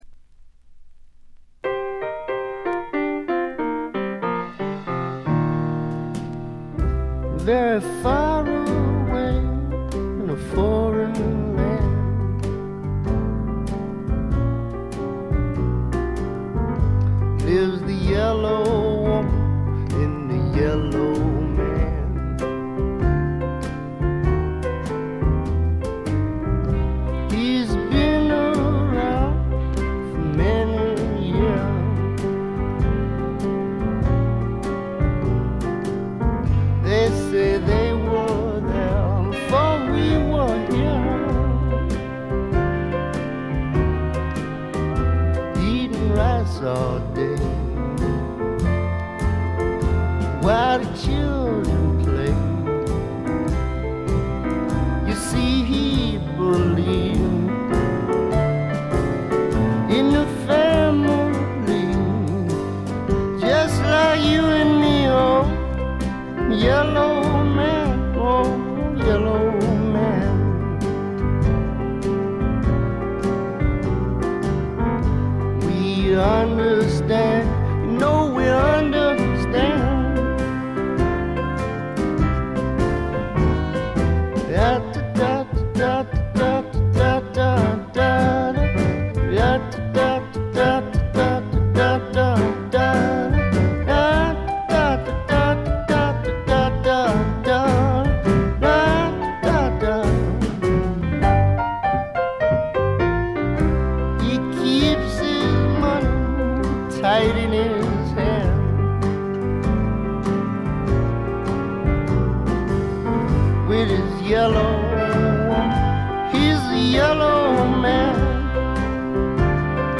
静音部で軽微なバックグラウンドノイズが少し。
試聴曲は現品からの取り込み音源です。
vocals, piano